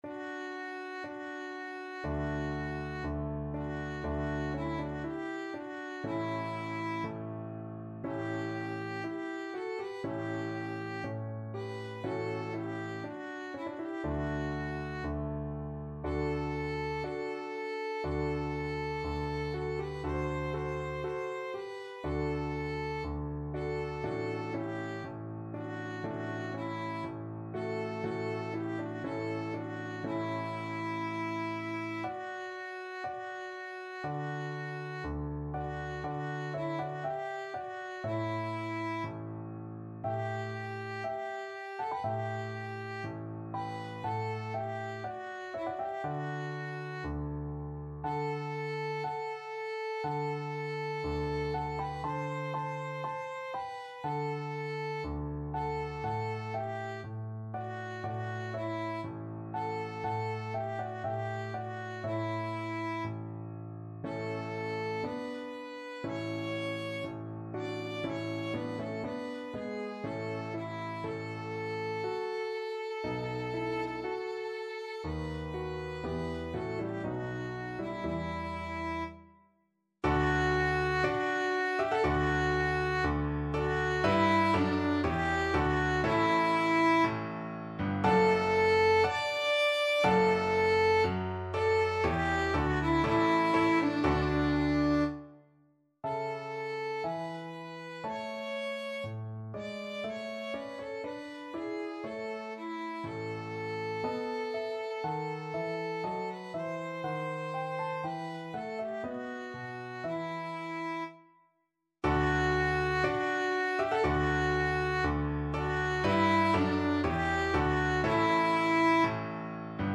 4/4 (View more 4/4 Music)
Slow =c.60
Classical (View more Classical Violin Music)